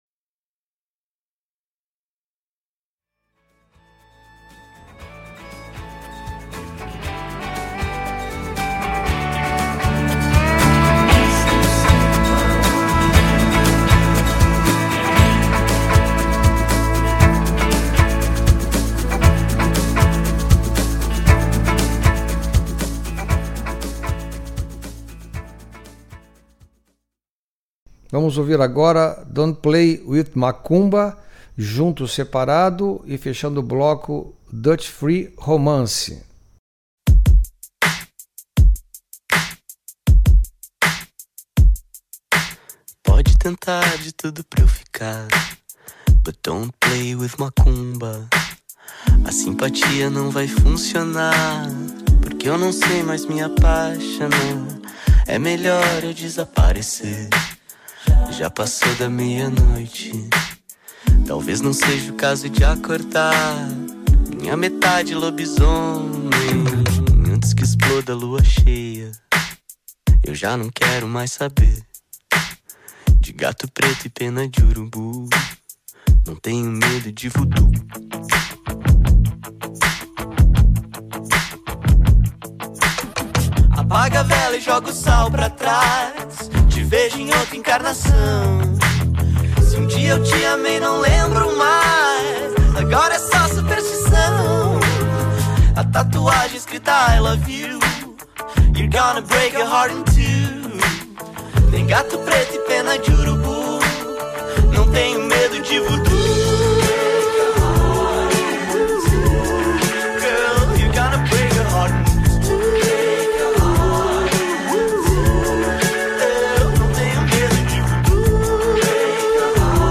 bateria
guitarra
baixo
piano e clarinete